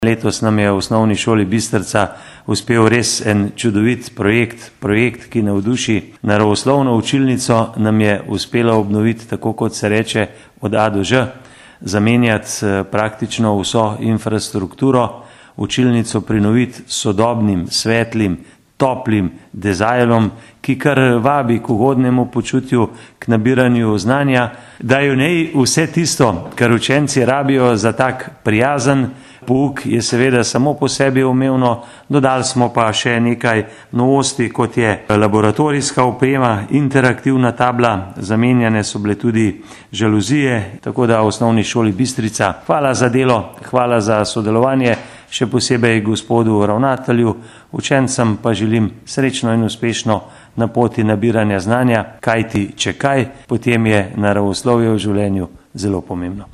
izjava_zupanobcinetrzicmag.borutsajoviconovinaravoslovniucilnici.mp3 (1,4MB)